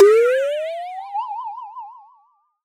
Casual Game Sounds U6